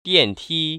[diàntī] 디앤티  ▶